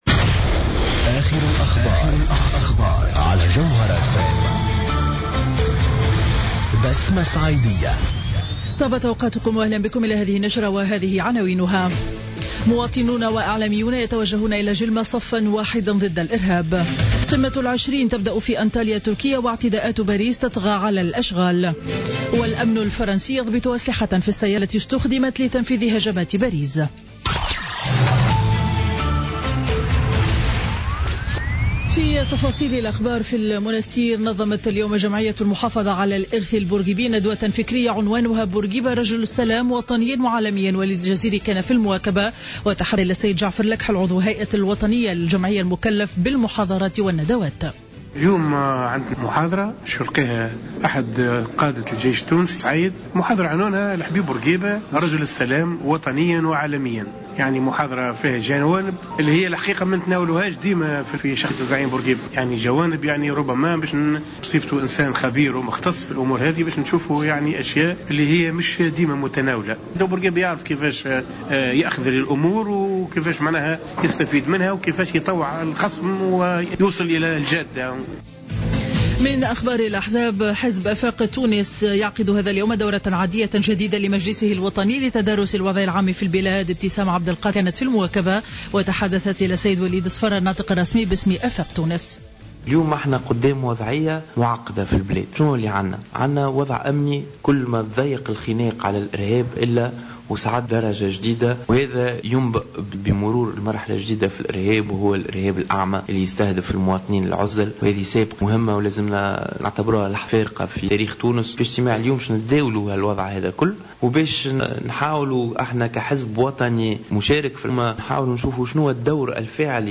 نشرة أخبار منتصف النهار ليوم الأحد 15 نوفمبر 2015